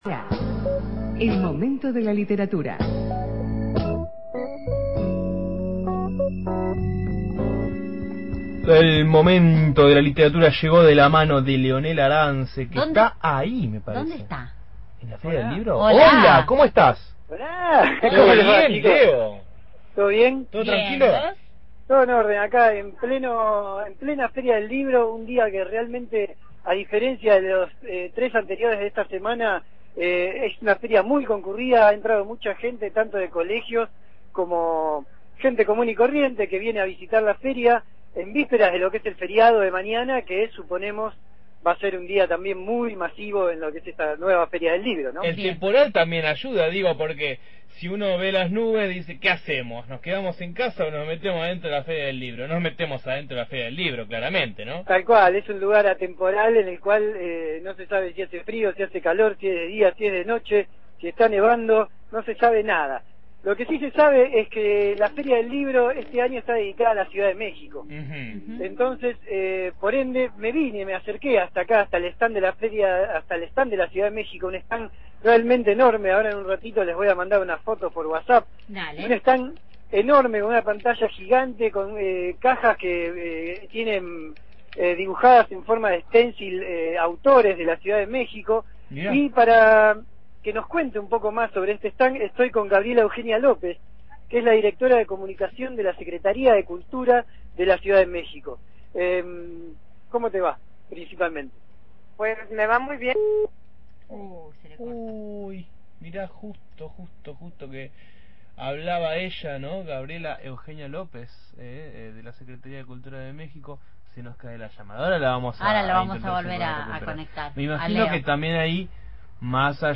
desde la Feria del Libro